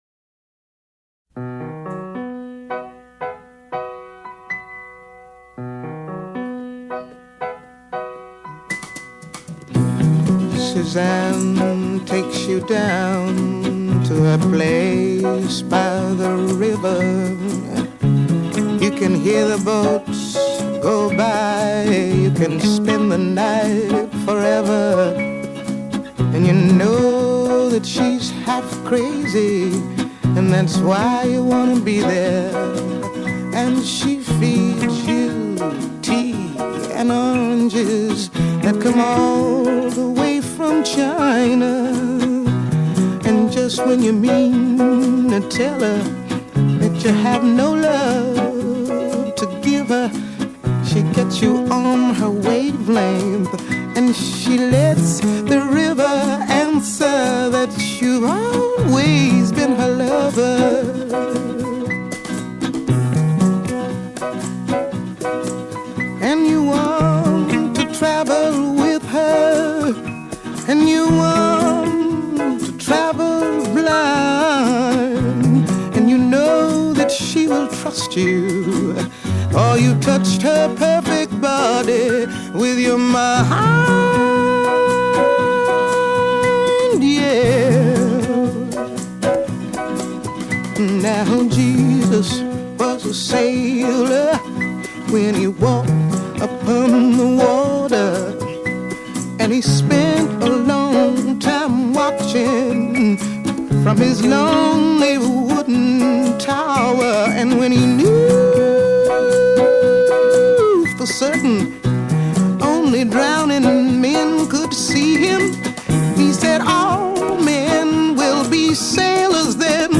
Jazz, Soul, Pop Rock